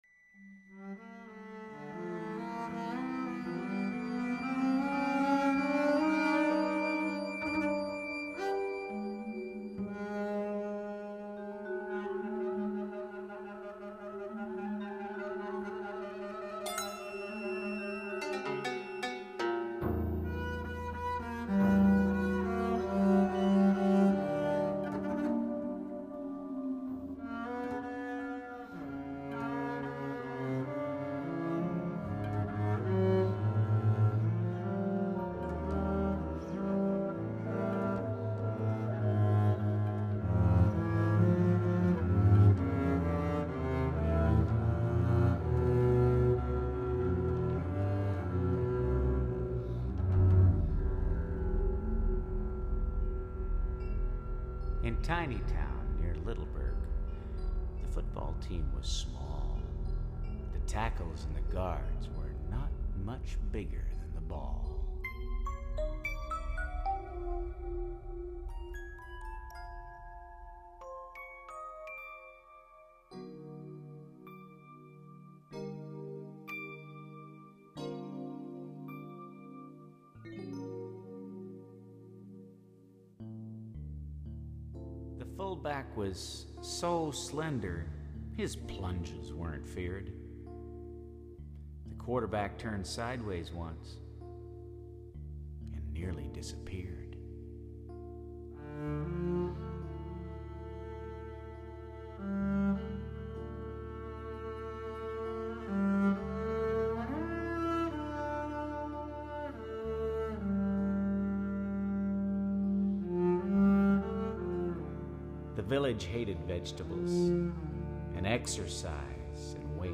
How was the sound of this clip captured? Please note: These samples are not of CD quality.